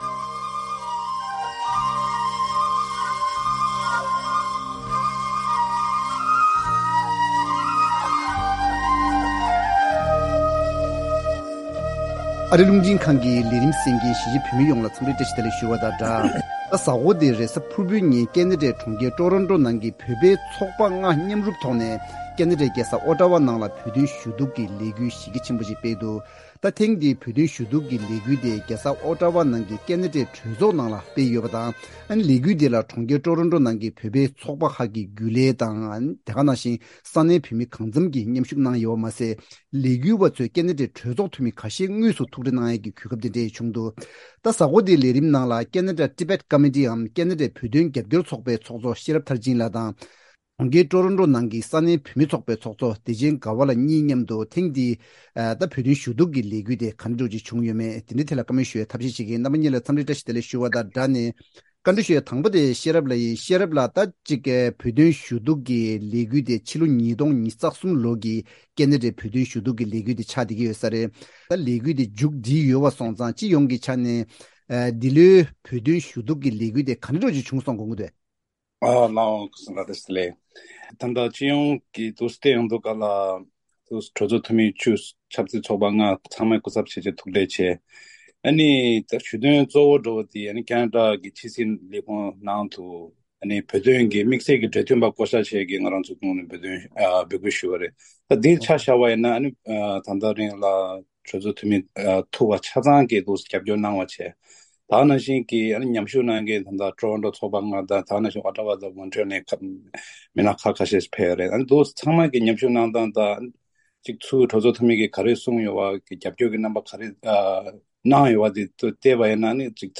TABLE TALK Importance and the Impact of Tibetan Lobby Efforts in Canada